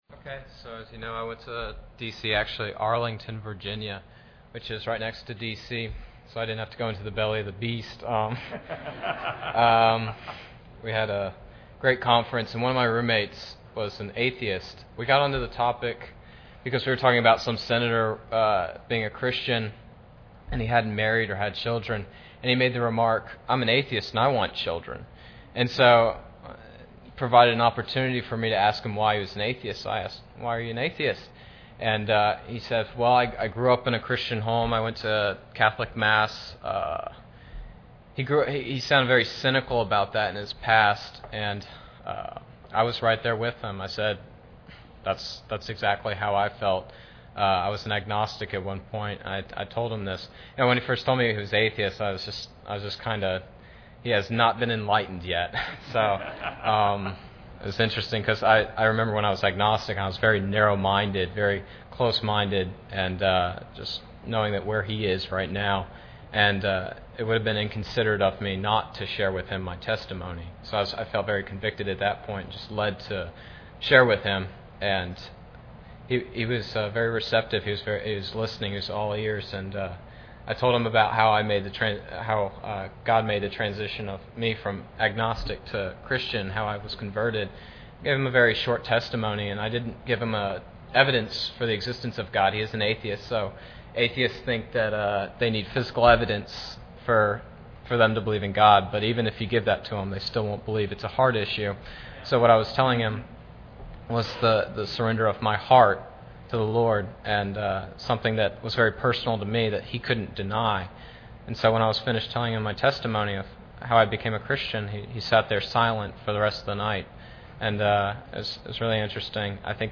Testimonies
Preacher: CCBC Members | Series: General
General Service Type: Sunday Evening Preacher